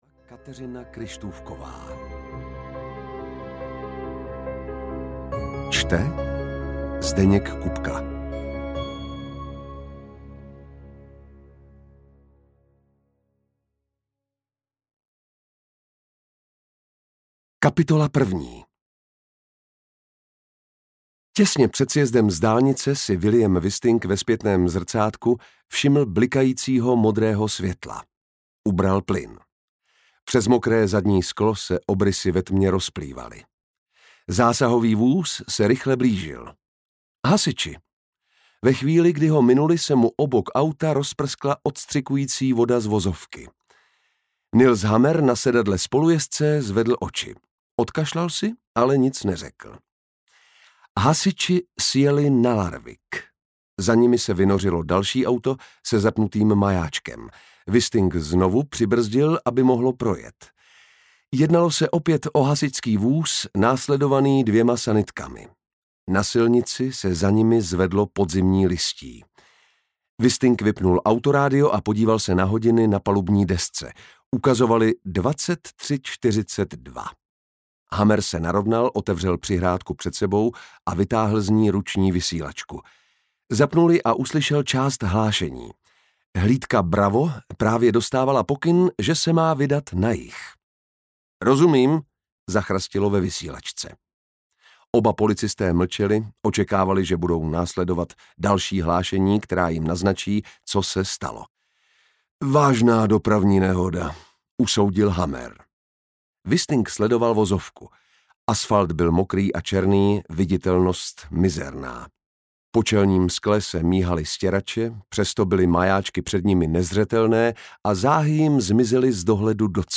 Zrádce audiokniha
Ukázka z knihy
zradce-audiokniha